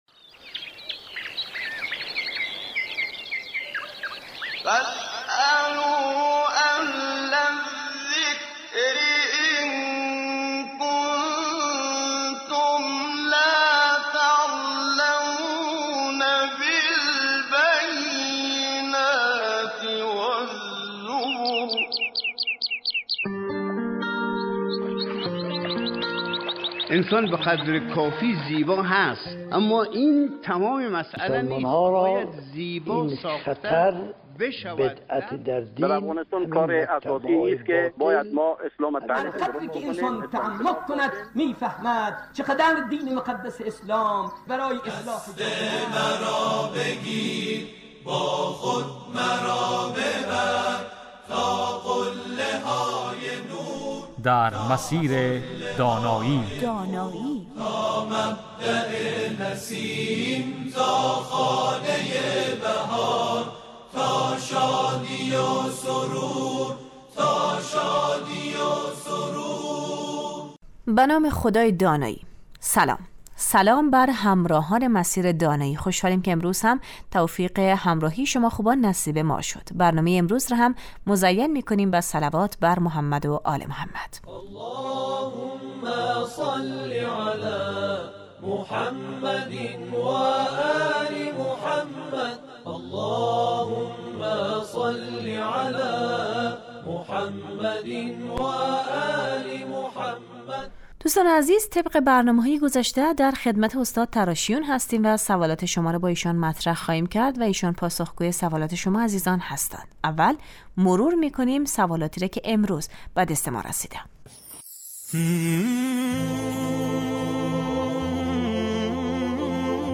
این برنامه 20 دقیقه ای هر روز بجز جمعه ها ساعت 11:35 از رادیو دری پخش می شود